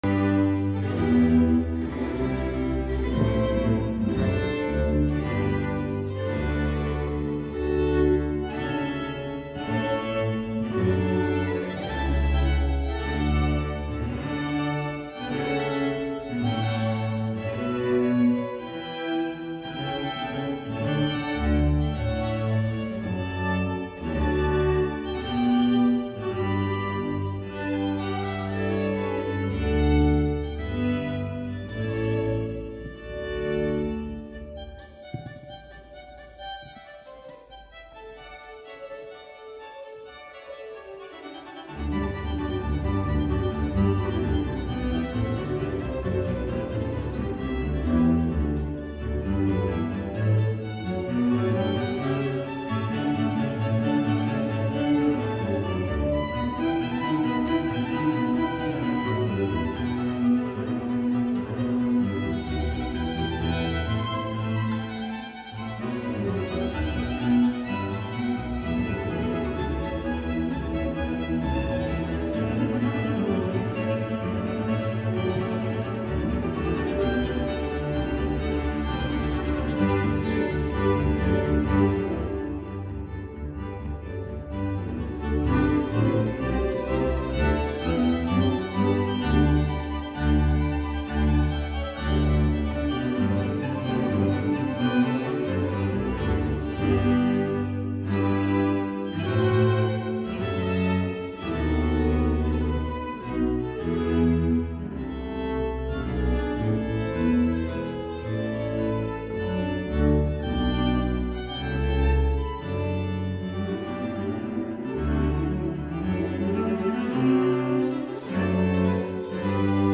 Lundi 15 janvier, le collège a accueilli à l'espace ARMONIA, des musiciens de l'Orchestre de Chambre de Toulouse.
Les élèves de 6ème ont bénéficié d'un concert de l'orchestre en quatuor et la découverte des instruments à corde.